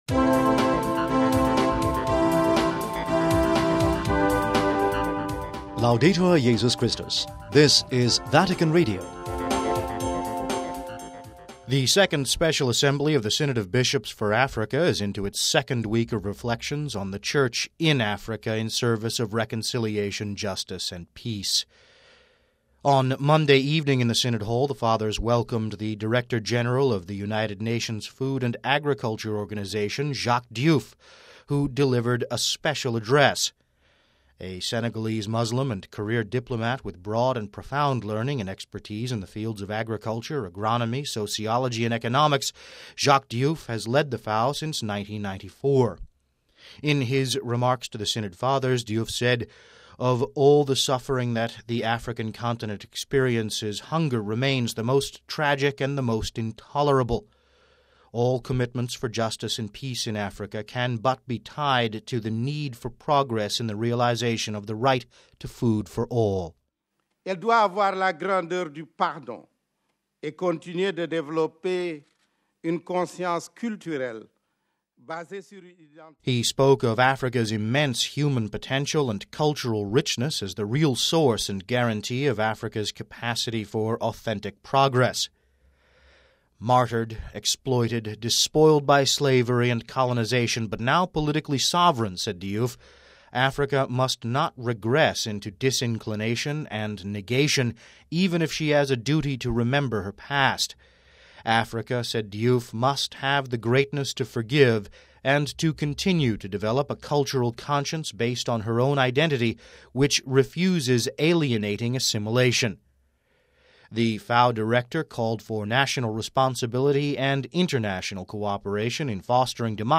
(13 Oct 09 - RV) The Director-General of the UN's Food and Agriculture Organisation, Jacques Diouf addressed the 2nd Special Assembly of the Synod of Bishops for Africa in General Congregation on Monday afternoon. We have this report...